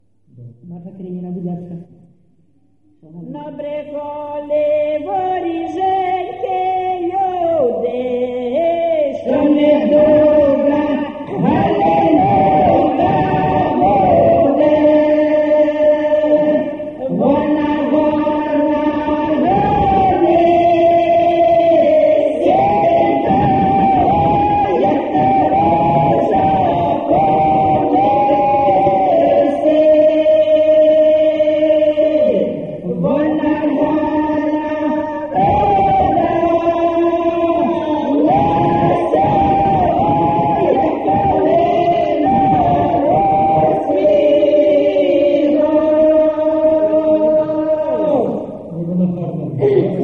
ЖанрВесільні
Місце записус. Веселий Поділ, Семенівський район, Полтавська обл., Україна, Полтавщина